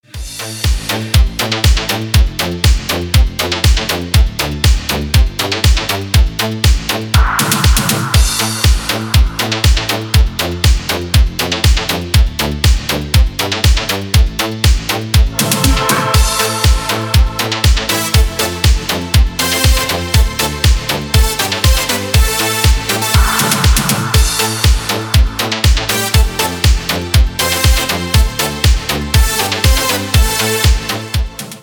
Рингтоны 80-х - 90-х